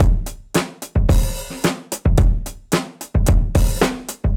Index of /musicradar/dusty-funk-samples/Beats/110bpm
DF_BeatB_110-02.wav